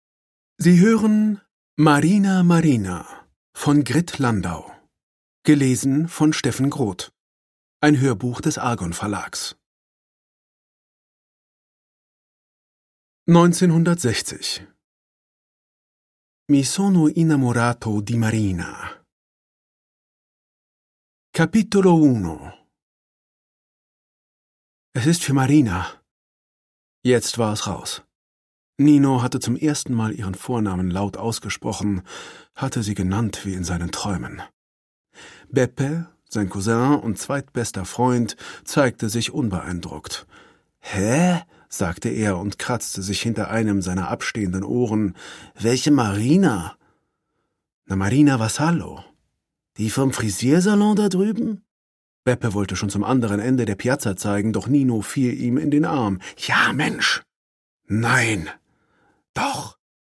Produkttyp: Hörbuch-Download
Gelesen von: Steffen Groth